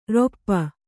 ♪ roppa